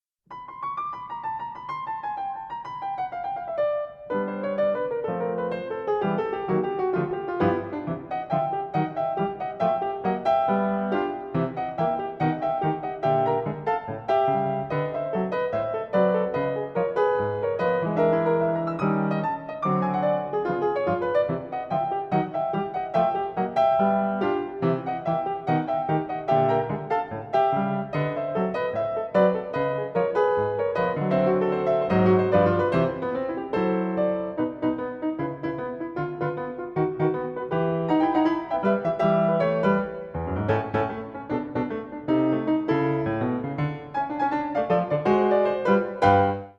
45 Medium tempo